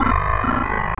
Lowengine.wav